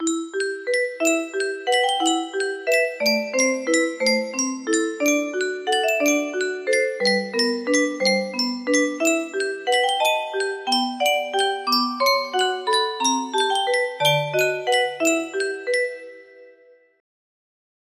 E MINOR music box melody